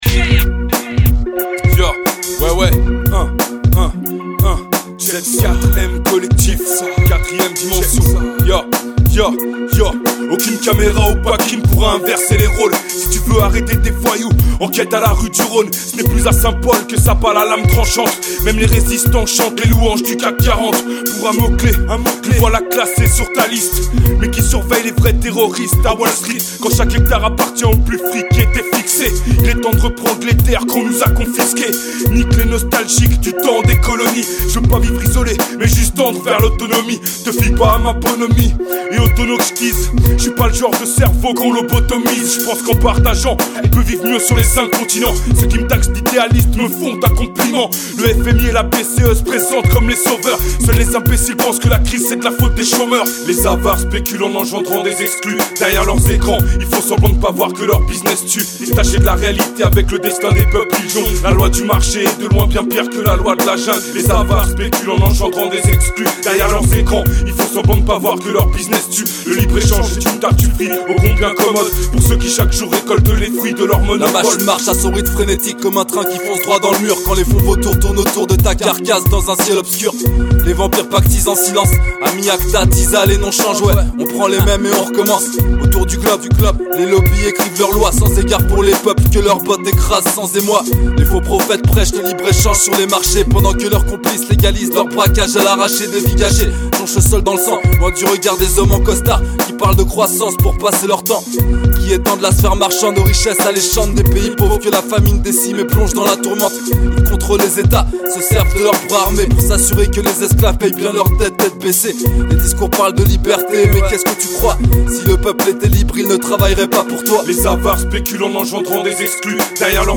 mc